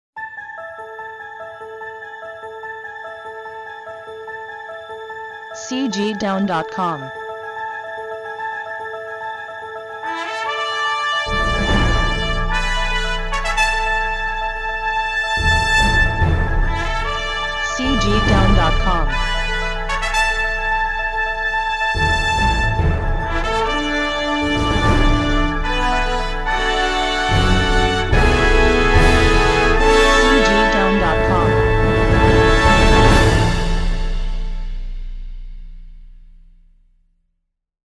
辉煌感动